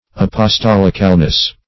Apostolicalness \Ap`os*tol"ic*al*ness\, n.